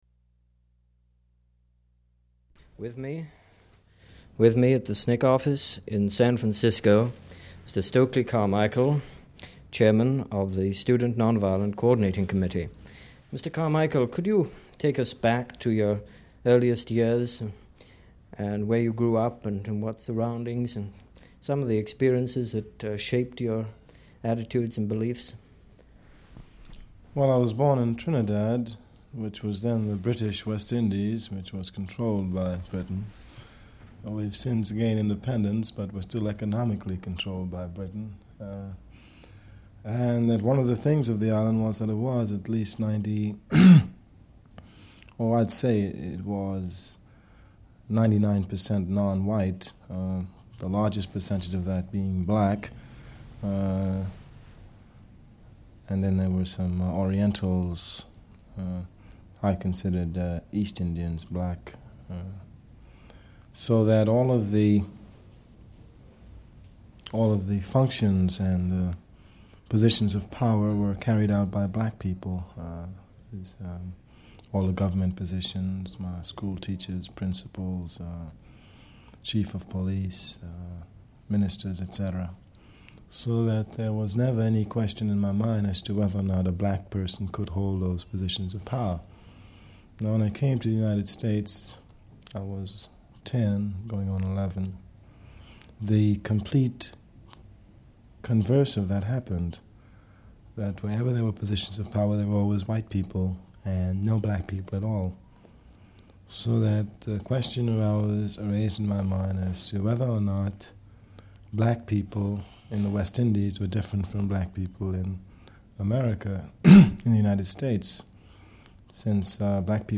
Interview with Stokely Carmichael: SF SNCC Office Part 1 [MP3]